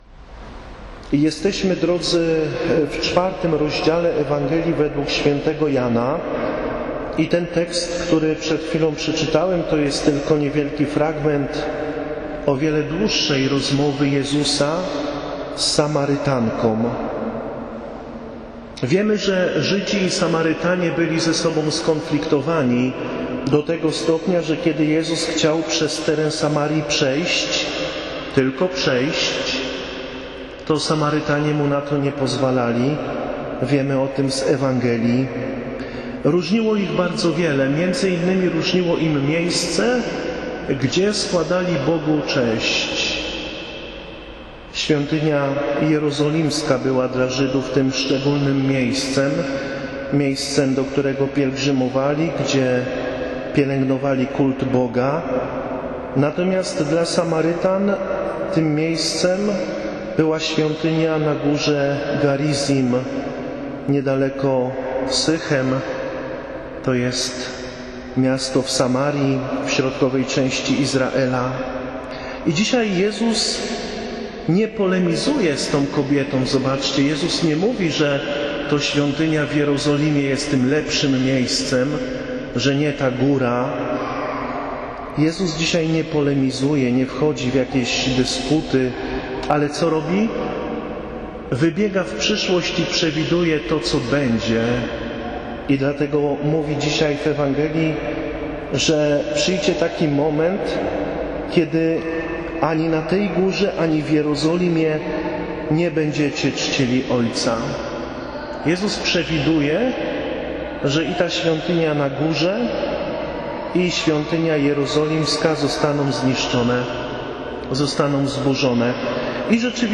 Audio - kazania w kościele